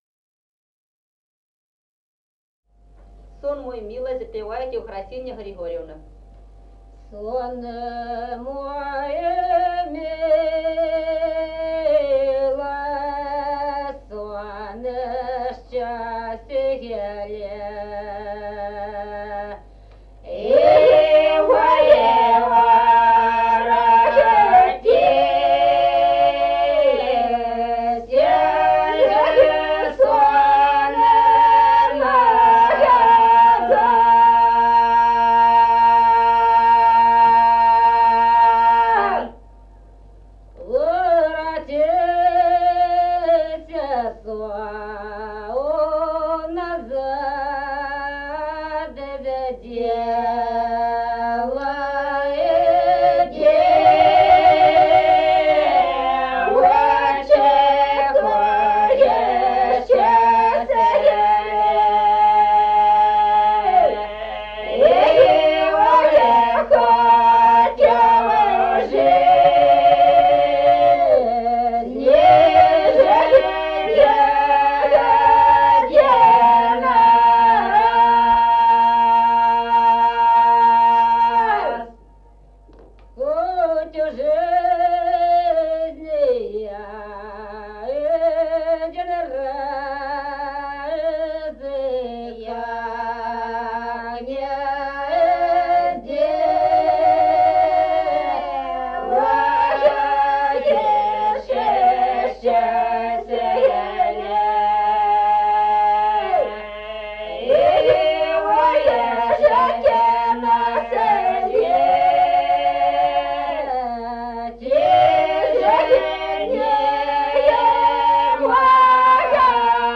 Песни села Остроглядово. Сон мой милый И 0059-02